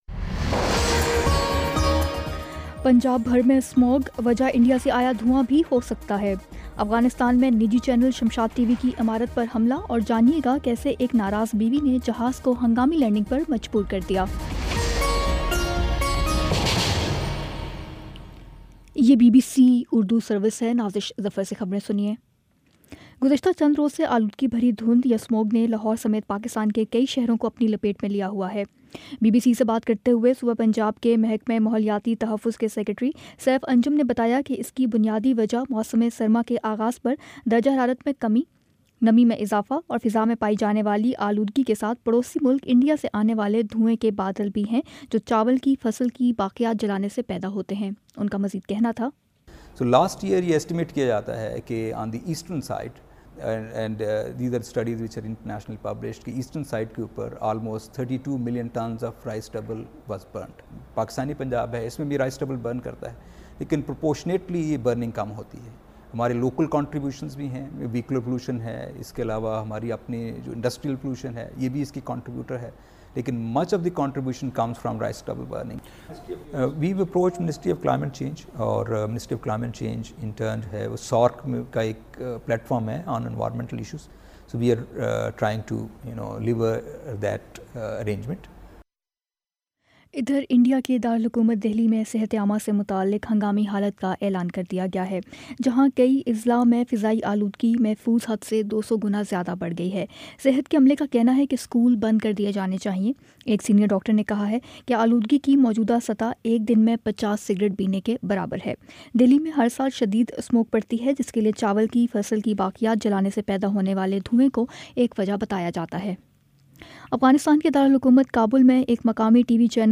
نومبر 07 : شام چھ بجے کا نیوز بُلیٹن